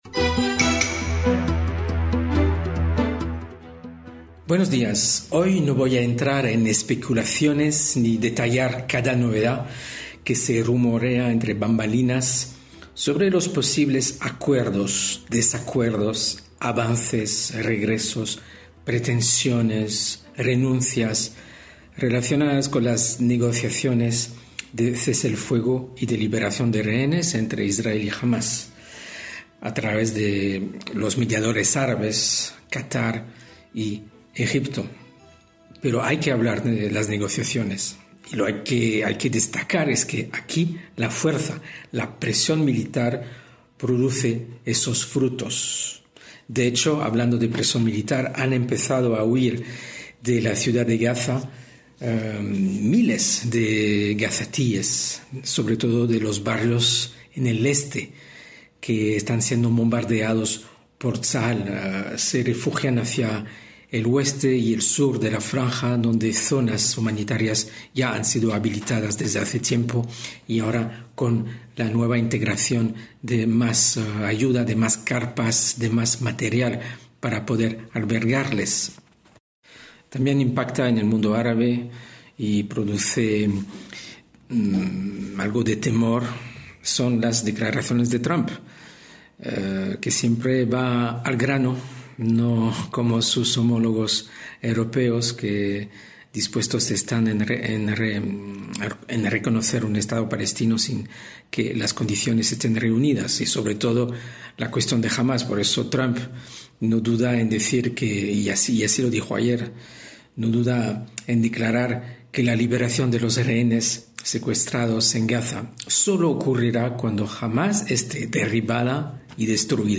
NOTICIAS DESDE ISRAEL